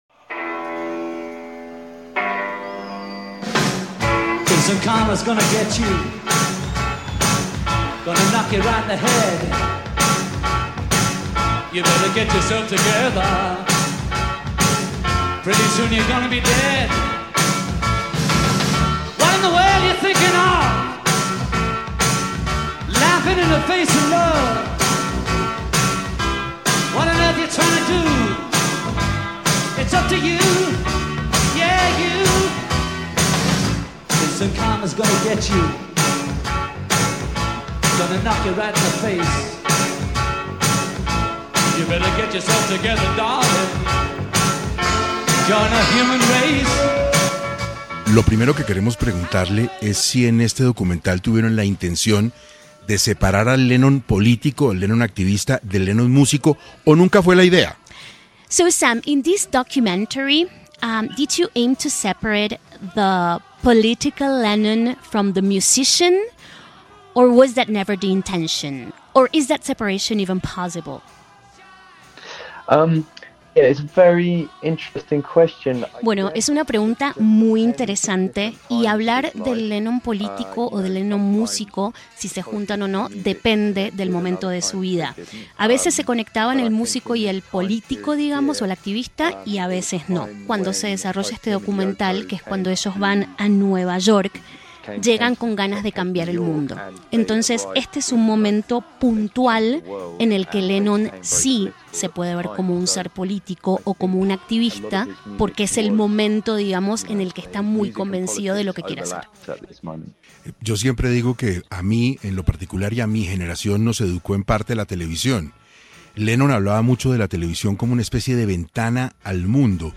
¿Fan de los Beatles? Escuche esta entrevista EXCLUSIVA con 6AM de Caracol Radio